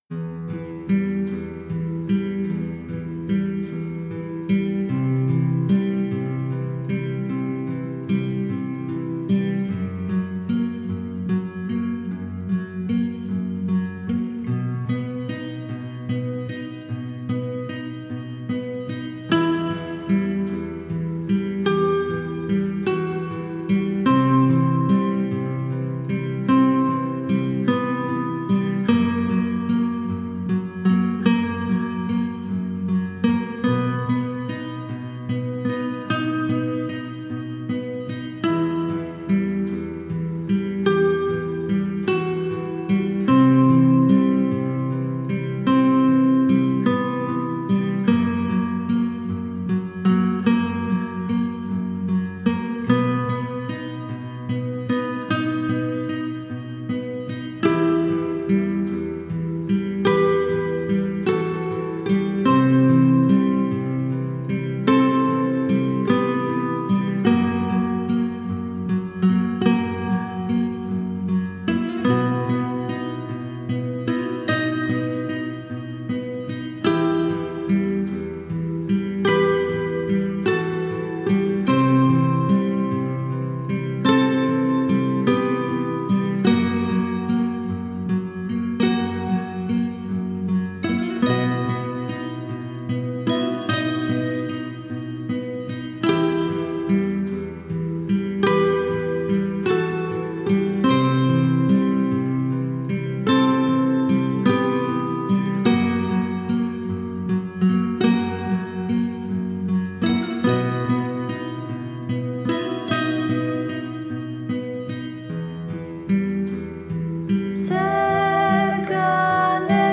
Альтернативная музыка Gothic Metal